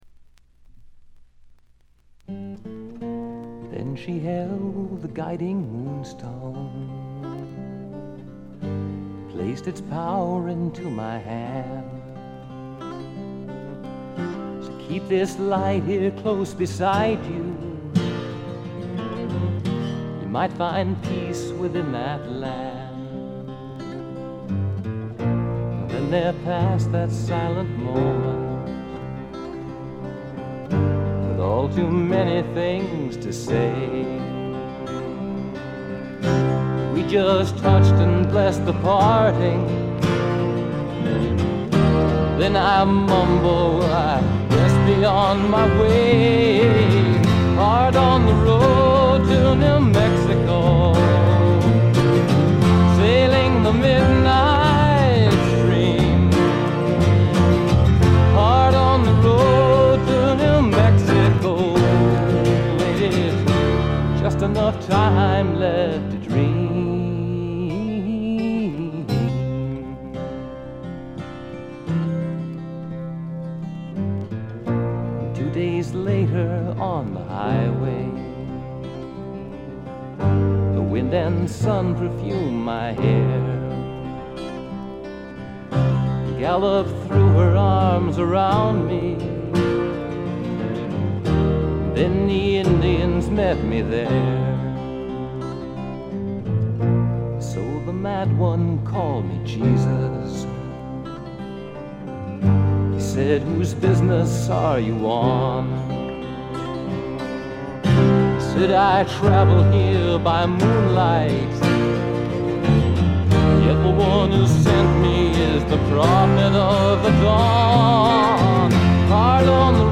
ほとんどノイズ感無し。
全編がフォーキーなアウトローの歌の数々。
試聴曲は現品からの取り込み音源です。